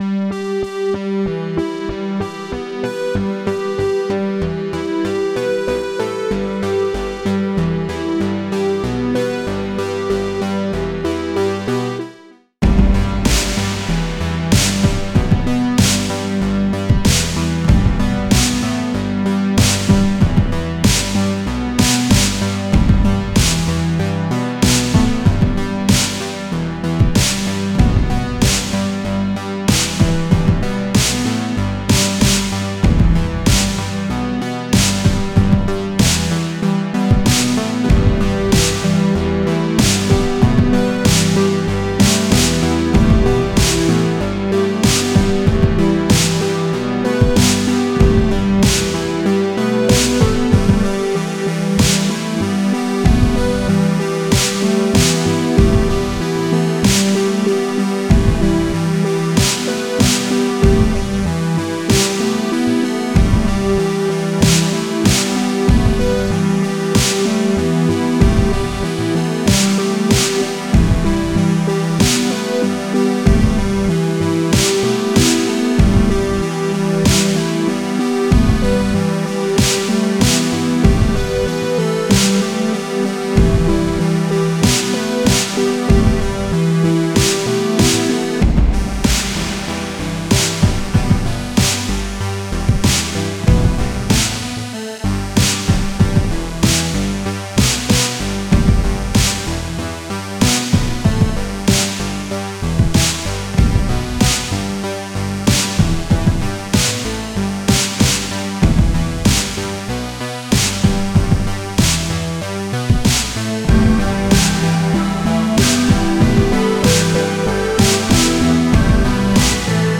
Жанр: Electronic, Italo Disco, Synth Pop, New Wave